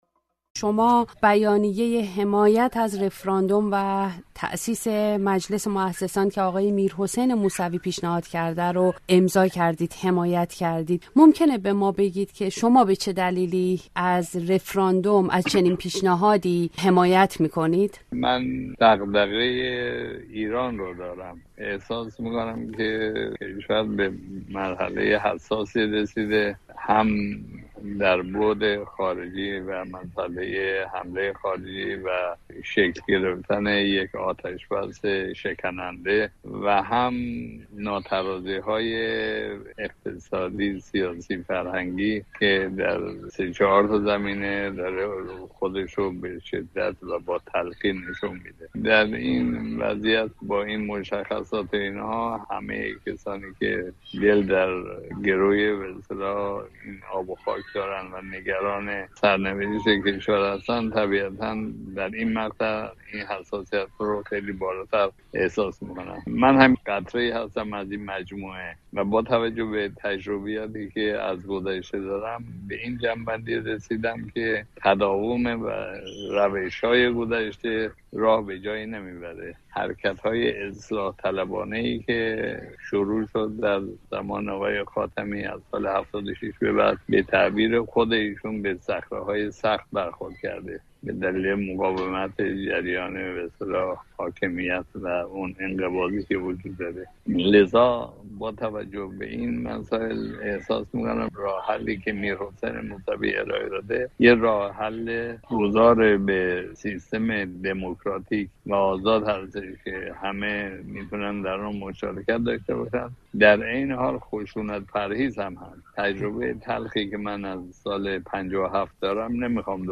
رفراندوم و تشکیل مجلس موسسان در گفت‌وگو با مرتضی الویری